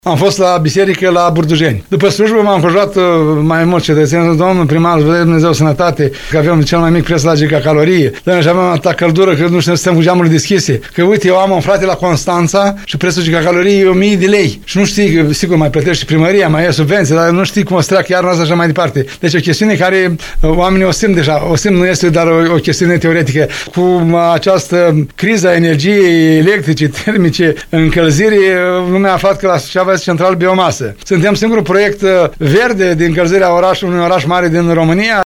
Primarul ION LUNGU susține că sistemul termic centralizat din municipiul Suceava își arată eficiența și în acest sezon rece, deoarece se înregistrează cel mai mic preț la gigacalorie din țară, la gardul centralei – 142 lei fără TVA.
El a prezentat și un dialog pe care l-a avut cu cetățenii, pe această temă.